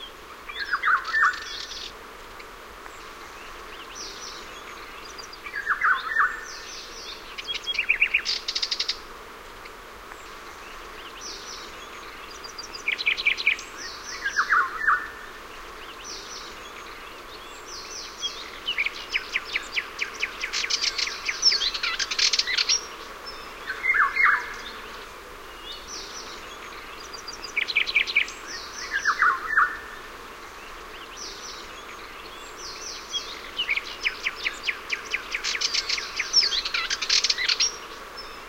day_forest.ogg